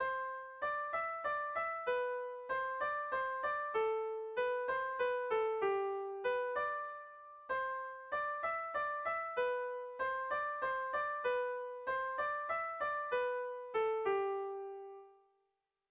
Bertso melodies - View details   To know more about this section
Irrizkoa
A1A2